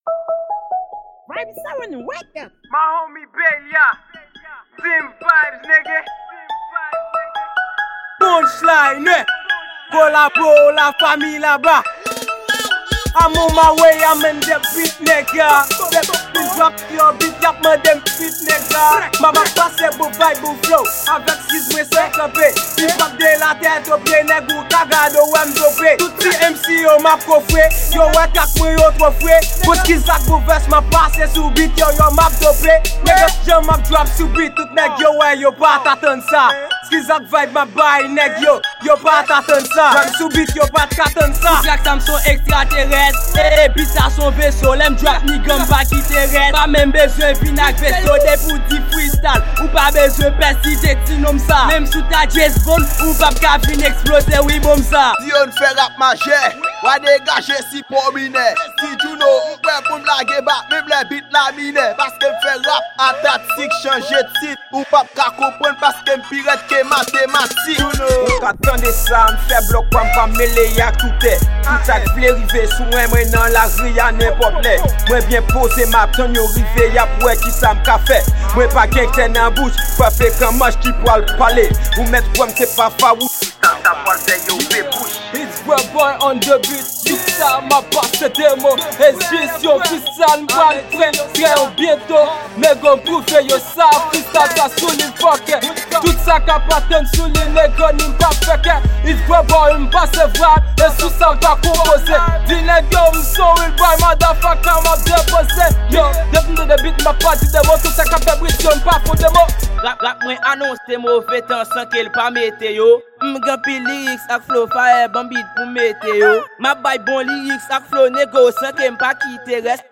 Genre: FREESTYLE.